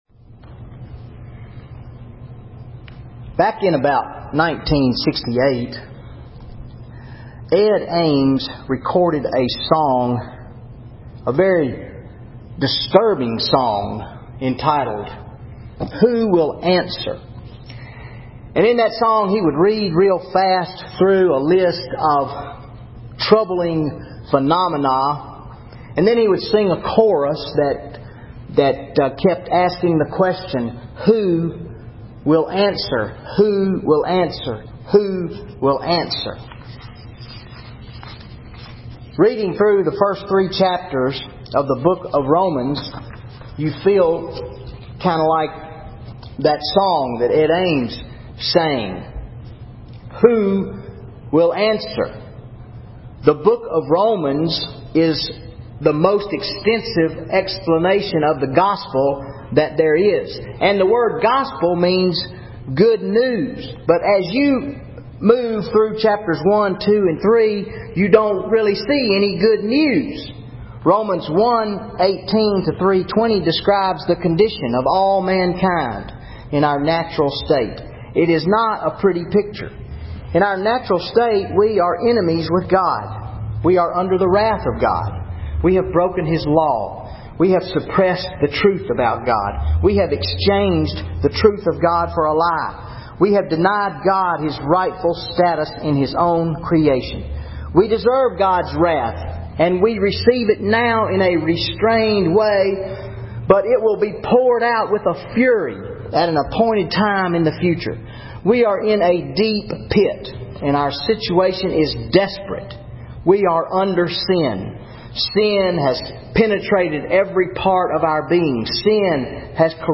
Sunday Sermon August 25, 2013 Romans 3:21-26
Sermon Audio